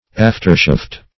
Aftershaft \Aft"er*shaft`\, n. (Zool.)